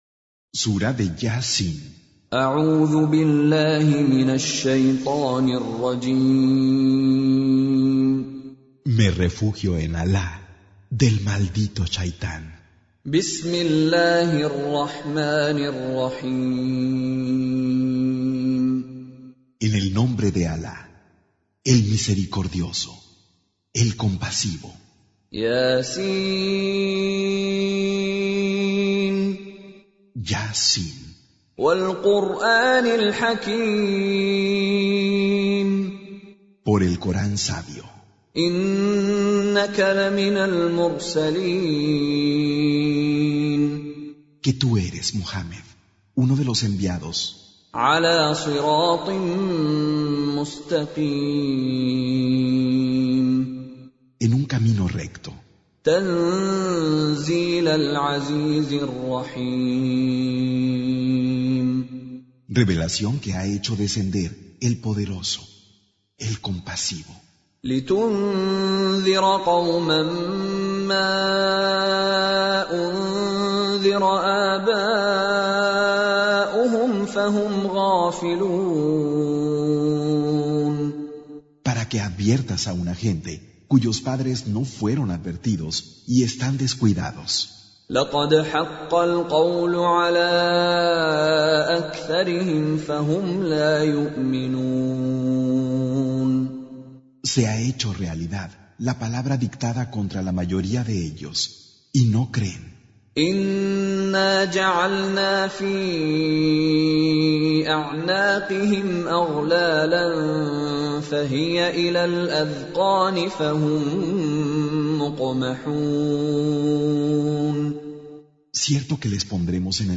Recitation
Surah Sequence تتابع السورة Download Surah حمّل السورة Reciting Mutarjamah Translation Audio for 36. Surah Y�S�n. سورة يس N.B *Surah Includes Al-Basmalah Reciters Sequents تتابع التلاوات Reciters Repeats تكرار التلاوات